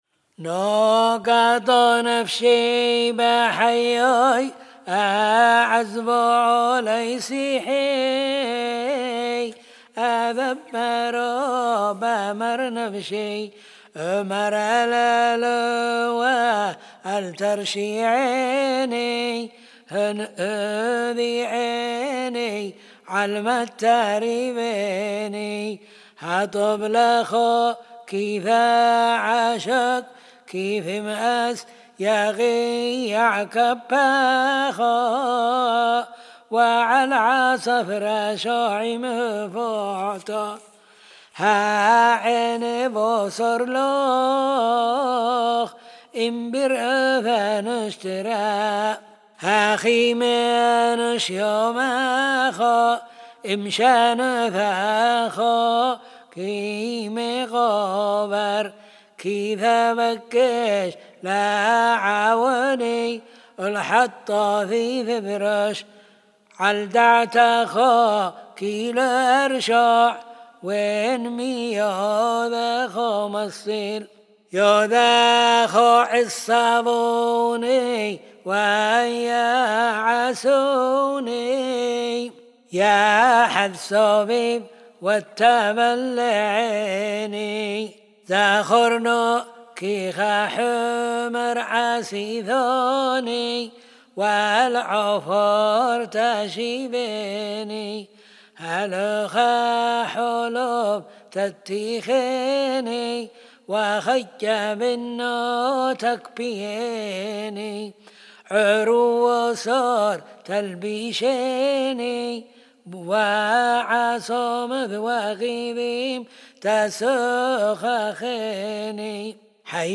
לכן תהנו מהמנגינה.